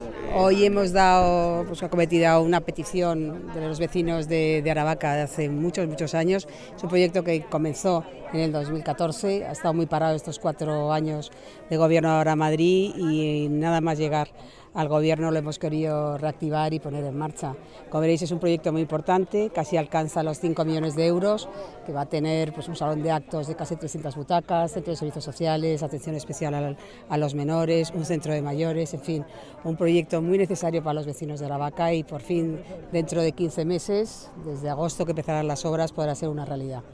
AUDIO-GARCIA-ROMERO-SOBRE-CENTRO-MAYORES-ARAVACA.mp3